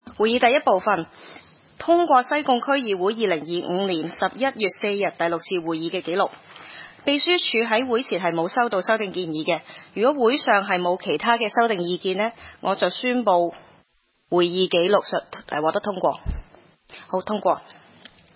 區議會大會的錄音記錄
西貢區議會第一次會議
西貢將軍澳政府綜合大樓三樓